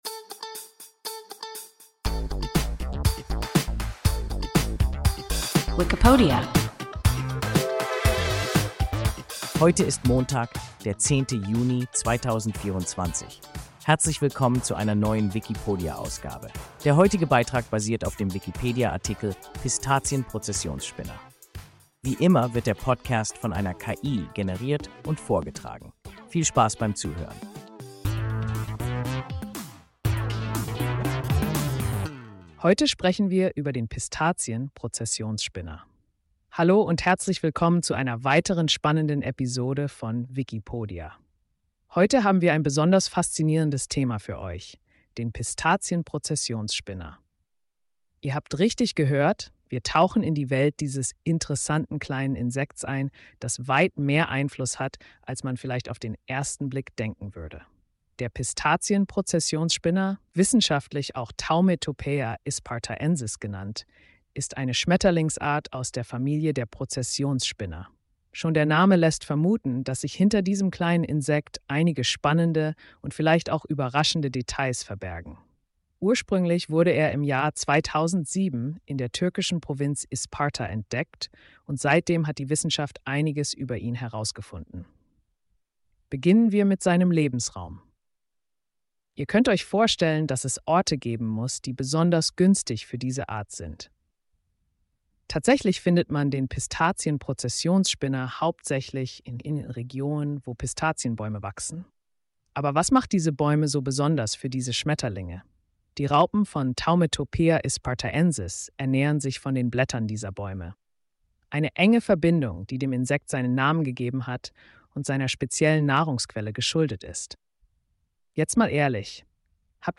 Pistazien-Prozessionsspinner – WIKIPODIA – ein KI Podcast